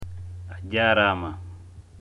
(excited)